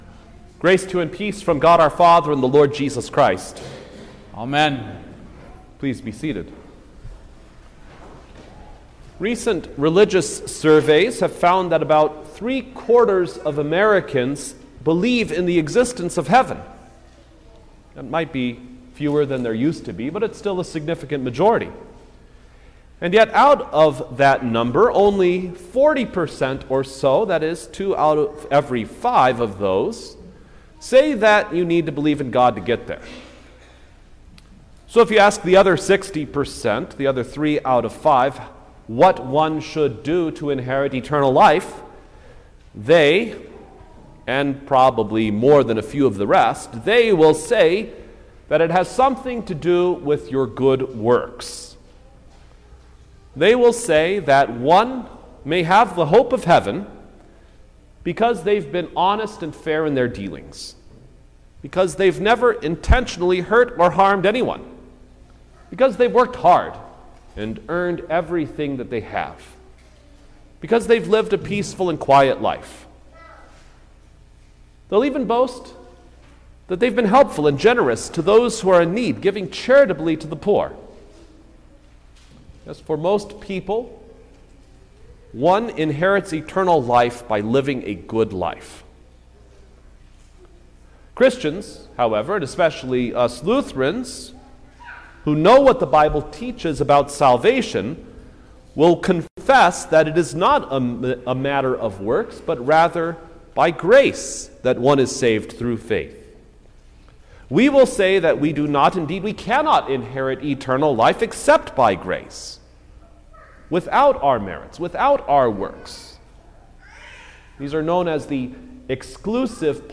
September-3_2023_Thirteenth-Sunday-after-Trinity_Sermon-Stereo.mp3